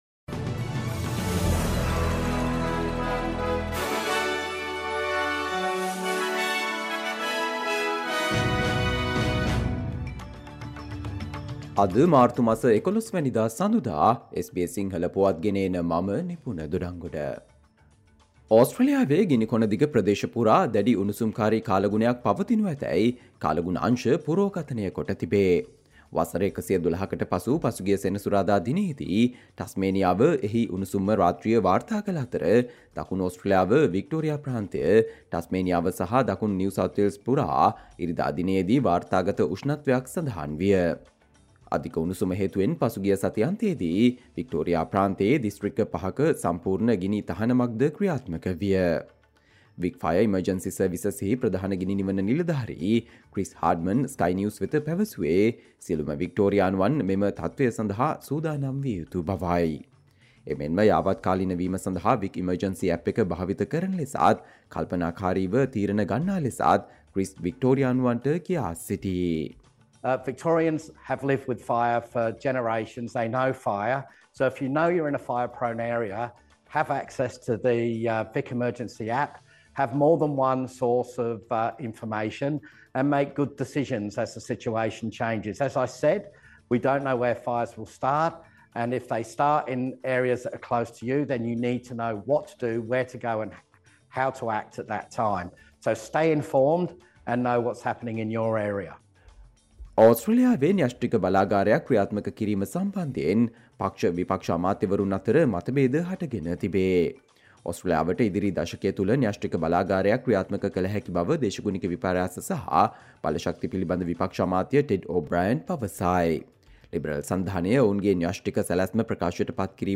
Australia news in Sinhala, foreign and sports news in brief - listen, Monday 11 March 2024 SBS Sinhala Radio News Flash